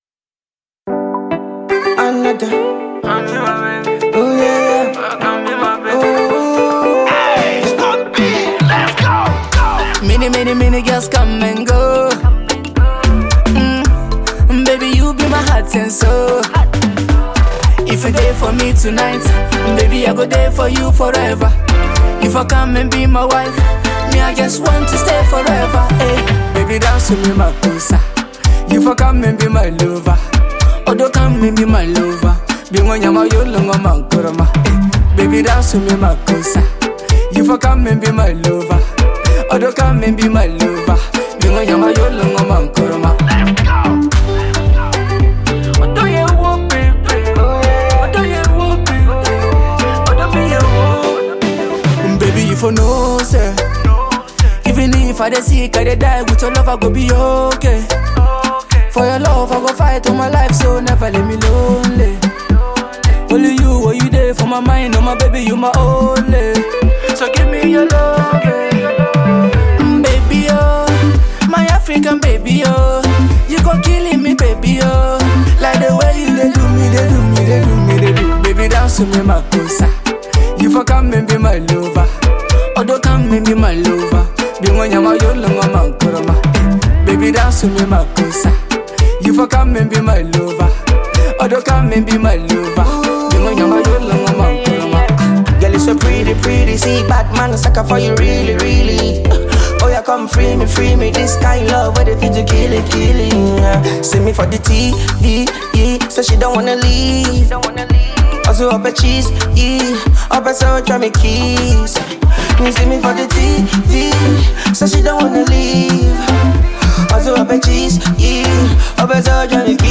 Ghana Music
contemporary Ghanaian music
dynamic vocal delivery
smooth vocals
energetic verses